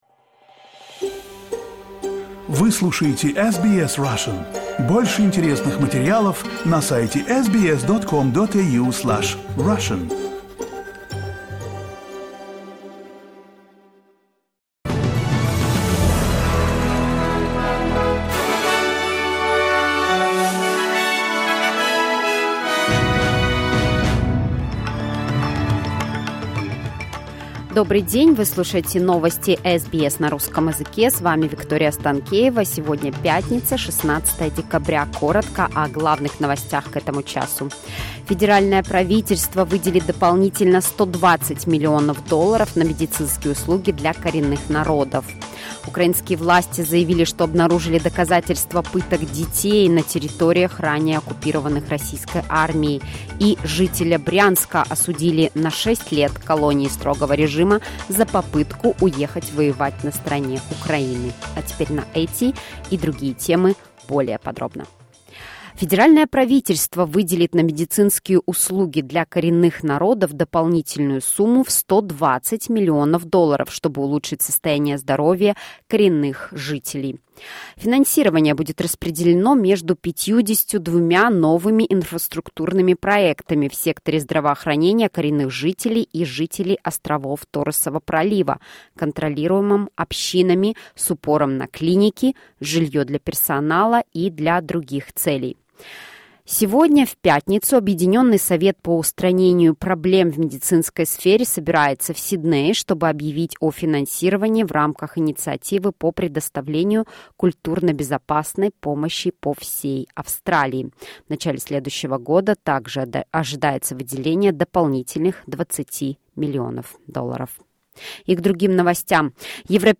SBS news in Russian — 16.12.2022